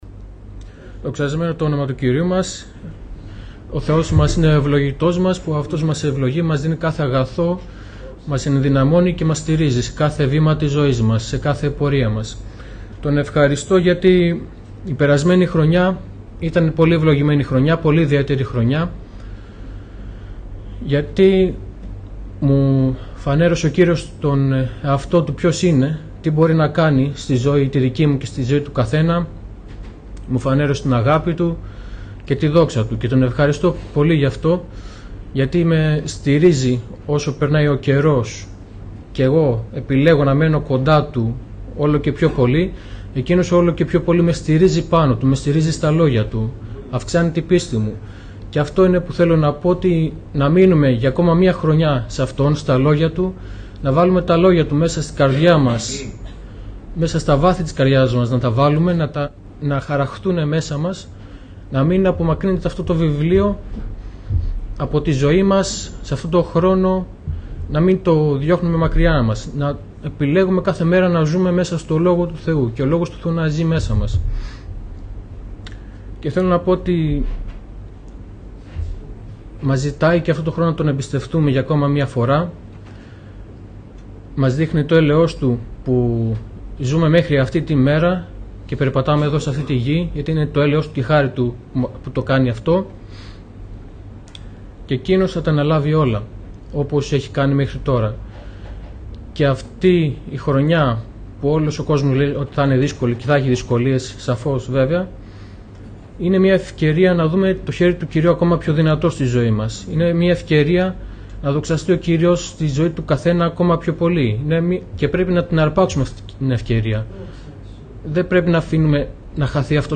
Μηνύματα απο αδελφούς Ομιλητής: Διάφοροι Ομιλητές Λεπτομέρειες Σειρά: Κηρύγματα Ημερομηνία: Παρασκευή, 02 Ιανουαρίου 2015 Εμφανίσεις: 409 Γραφή: 1 Ιωάννη 5:13 Λήψη ήχου Λήψη βίντεο